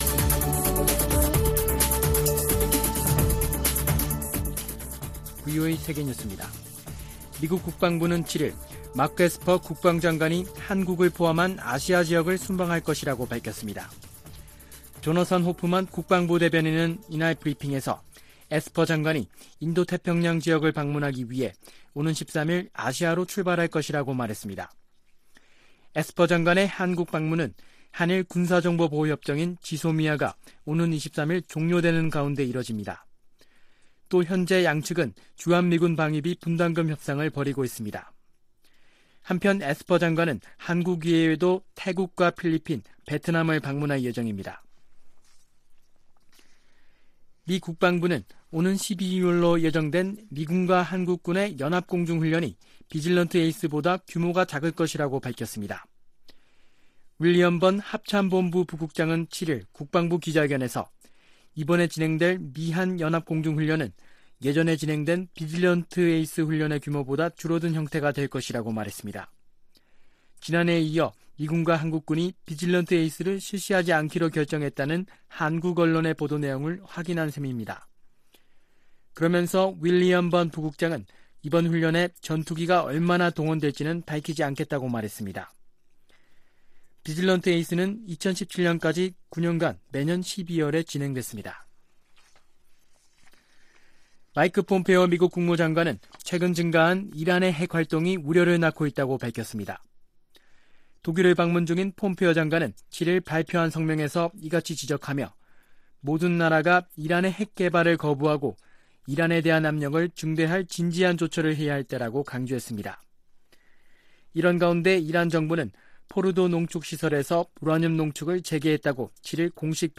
VOA 한국어 아침 뉴스 프로그램 '워싱턴 뉴스 광장' 2019년 11월 8일 방송입니다. 유엔 안보리의 제재에도 북한의 태도는 변하지 않았다며 제재로 인한 인도주의 피해를 줄어야 한다는 민간기관의 지적이 나왔습니다. 한국 정부가 북한에 금강산 시설에 대한 남측 점검단의 방북을 제안한 가운데, 한국 통일부 장관이 남-북한이 마주 앉으면 실천 가능한 금강산 관광의 해법을 찾을 수 있다고 말했습니다.